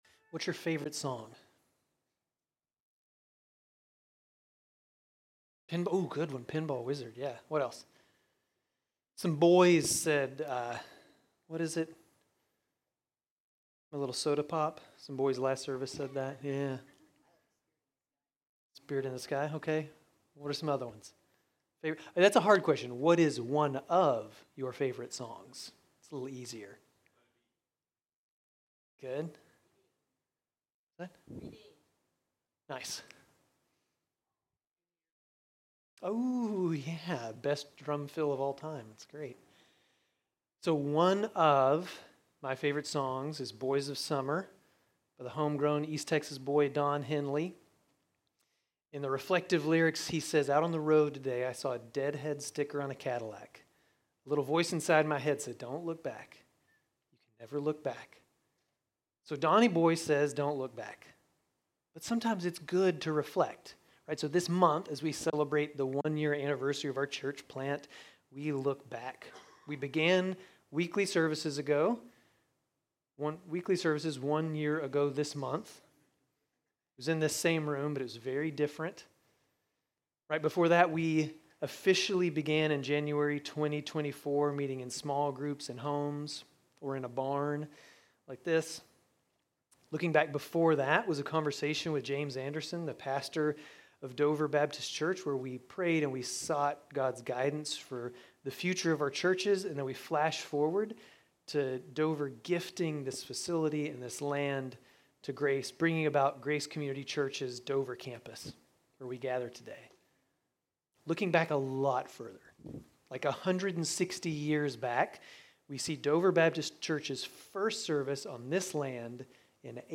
Grace Community Church Dover Campus Sermons 9_14 Dover Campus Sep 15 2025 | 00:23:40 1x 00:00 / 00:23:40 Subscribe Share RSS Feed Share Link Embed